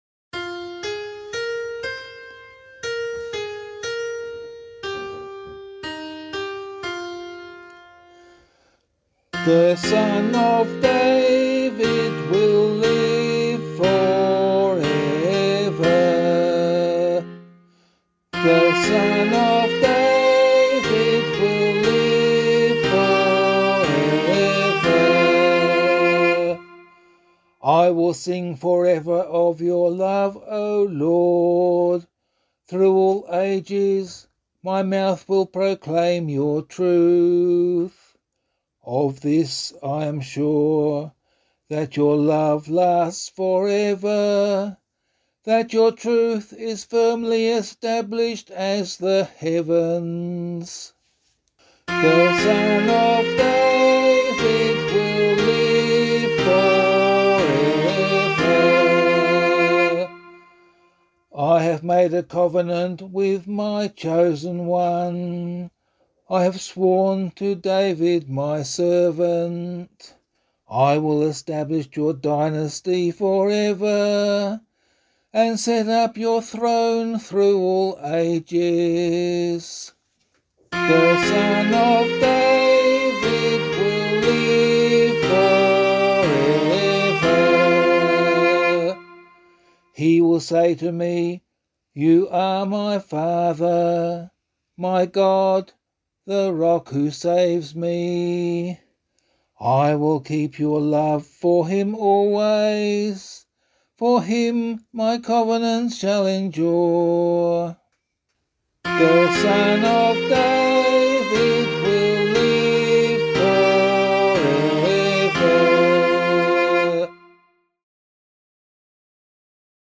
178 Joseph Psalm [LiturgyShare 2 - Oz] - vocal.mp3